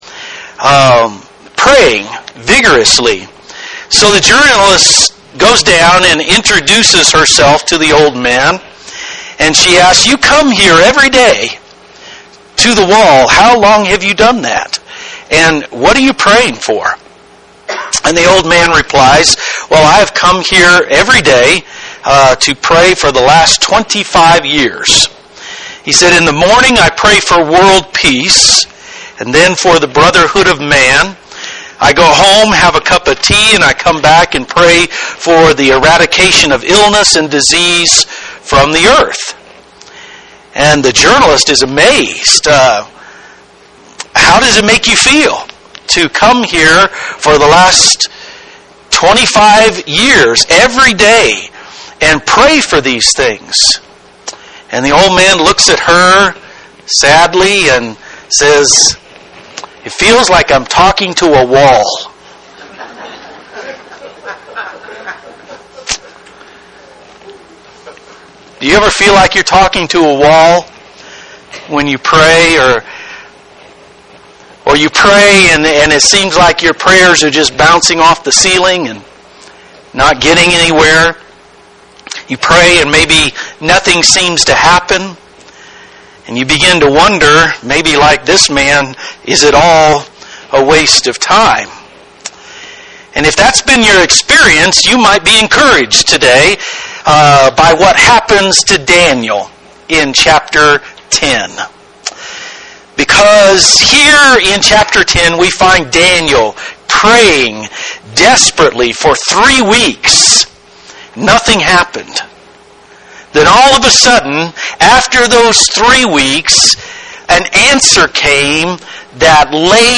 A Glorious Vision - New Covenant Baptist Church